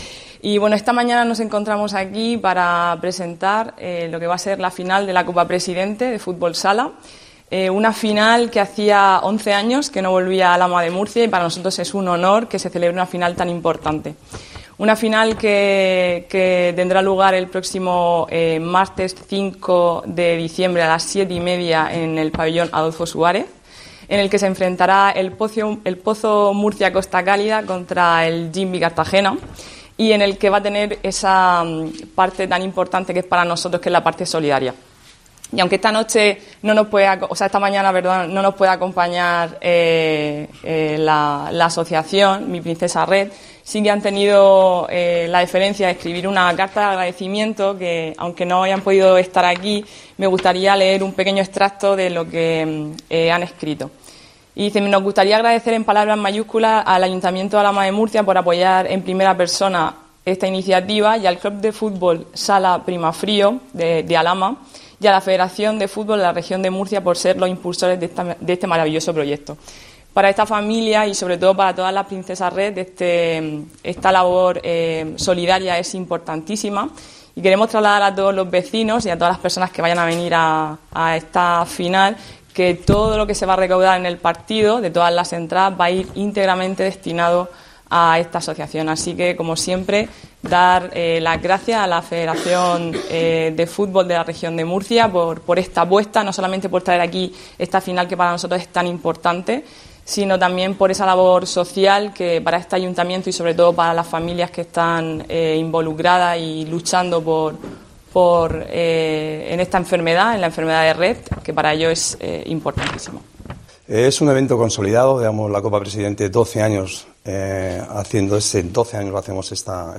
Presentación en Alhama de la final de la Copa Federación